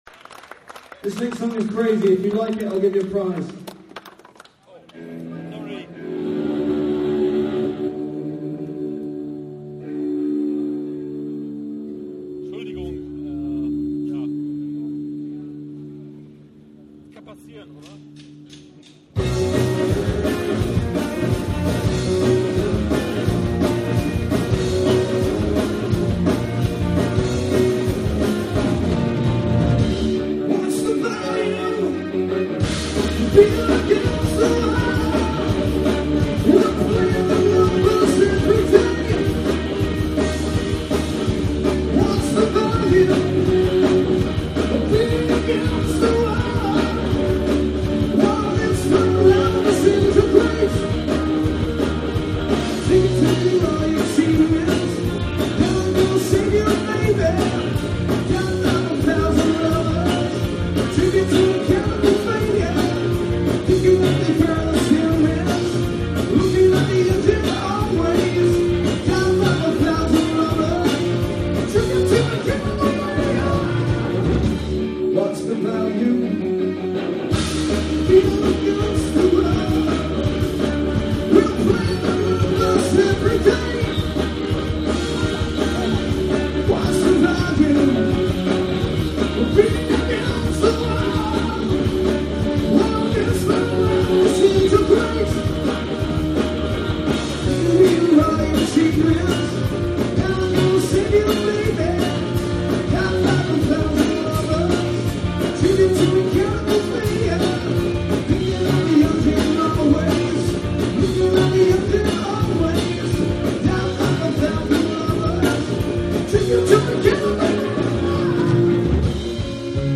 Aug. 11,2001 HALDERN
bass guitar